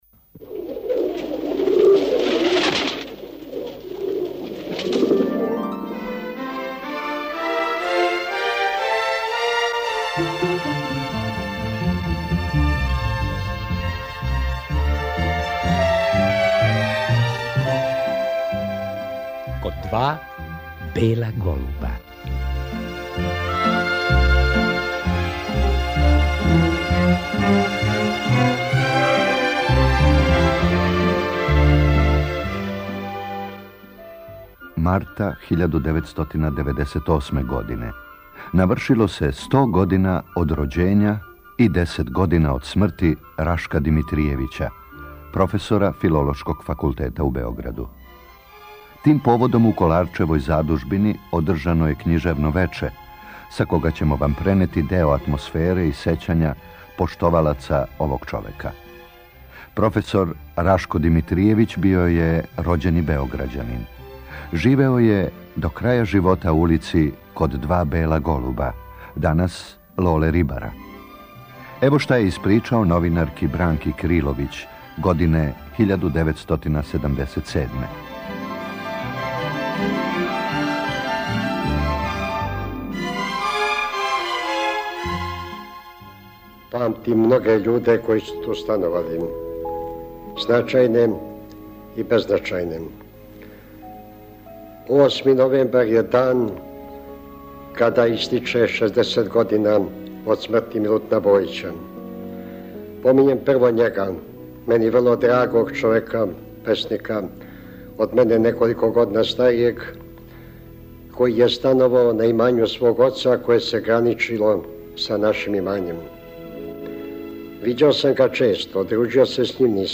Поводом стогодишњице његовог рођења, 1998. године у Коларчевој задужбини уприличено је књижевно вече.